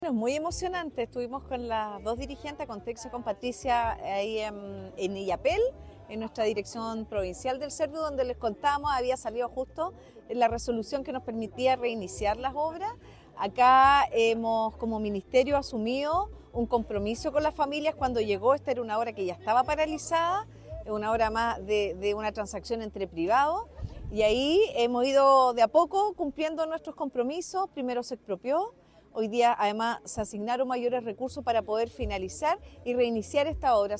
Gabriela Elgueta – Subsecretaria MINVU